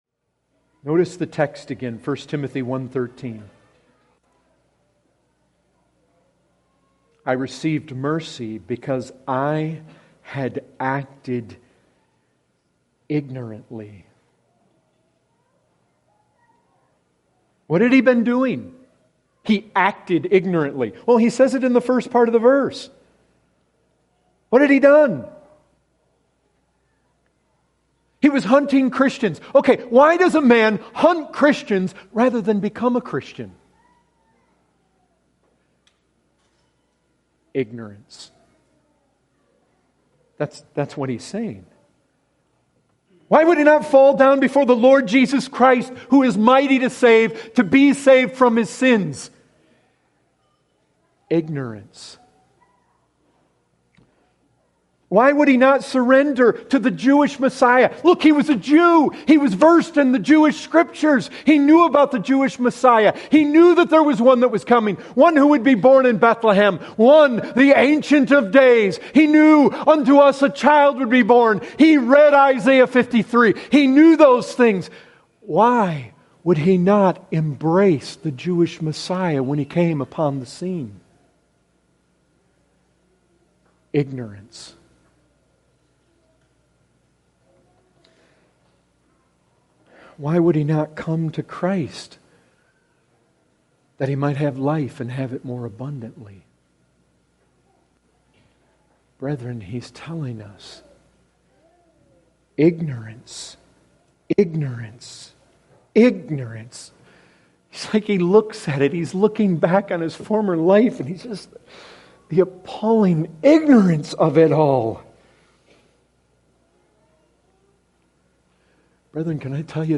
Excerpt taken from the full sermon Mercy for the Chief of Sinners .